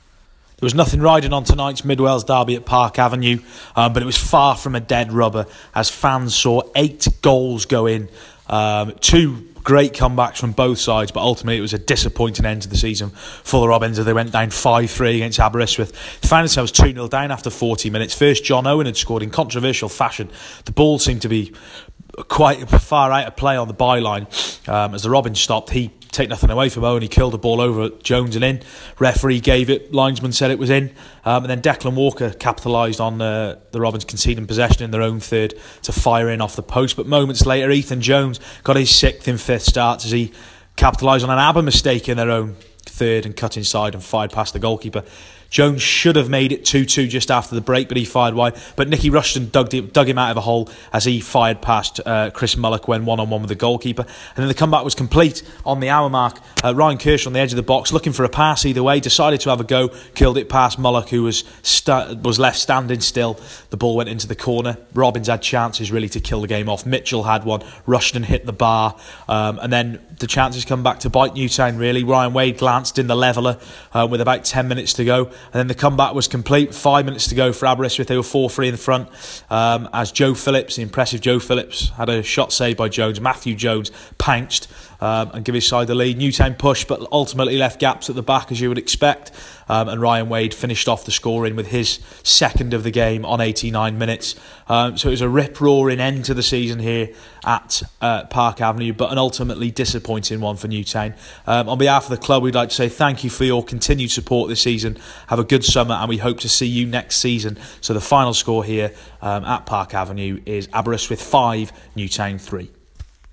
AUDIO REPORT - Aber 5-3 Robins